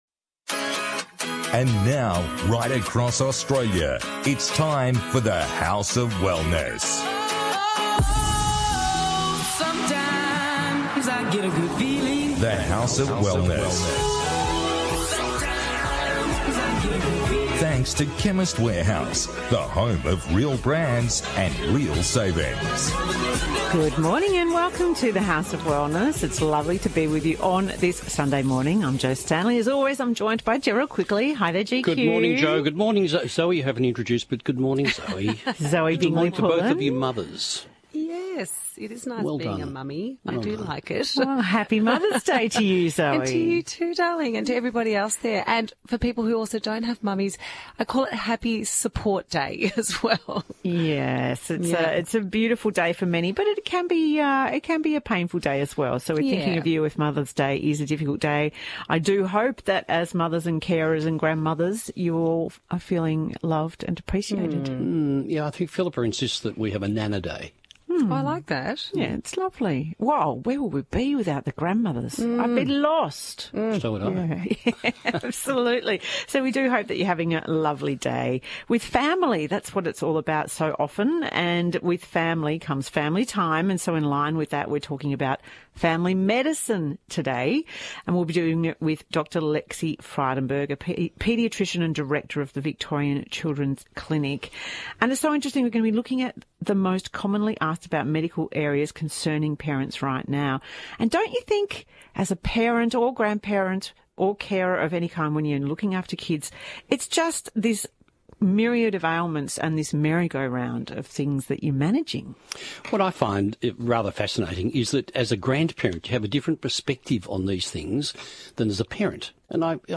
This week, the team discusses children’s health, liposomal vitamins and more.